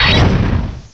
cry_not_larvesta.aif